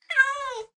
meow1.ogg